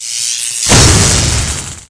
b_leidian.mp3